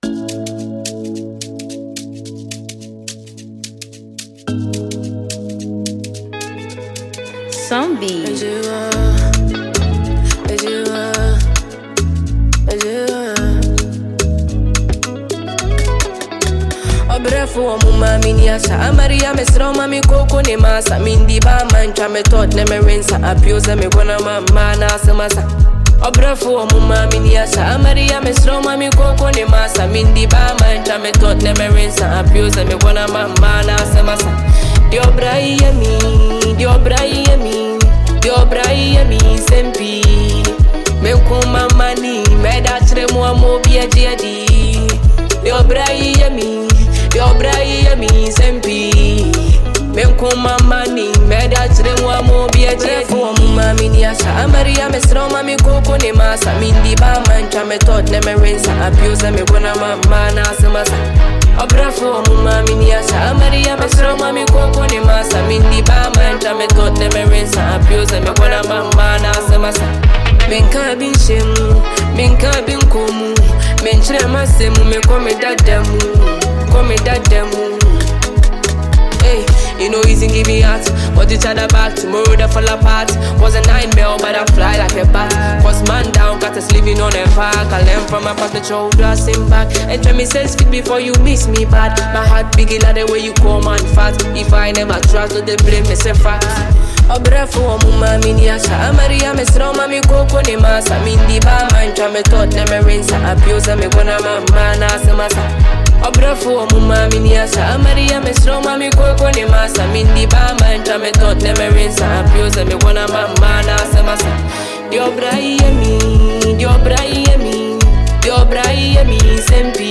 Fast and emerging Ghanaian female songstress